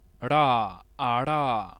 ملف:Retroflex flap.ogg - المعرفة
Retroflex_flap.ogg.mp3